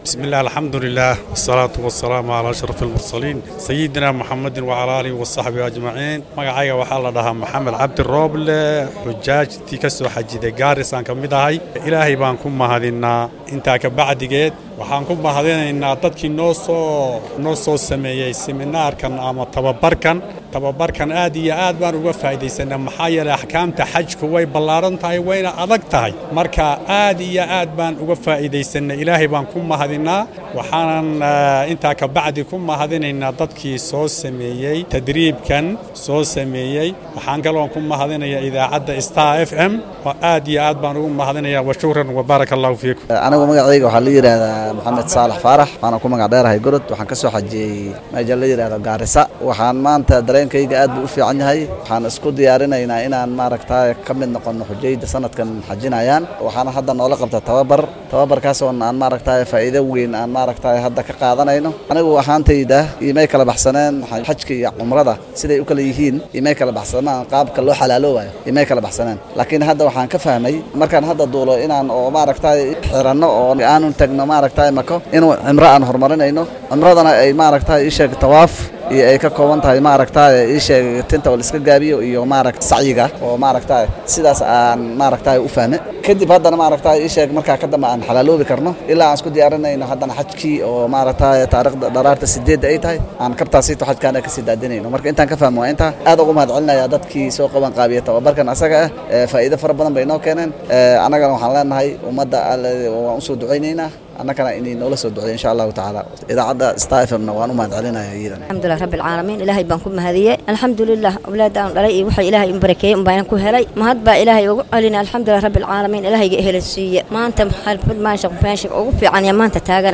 Qaar ka mid ah dadka maanta tababarka la siiyay ayaa dareenkooda la wadaagay warbaahinta Star.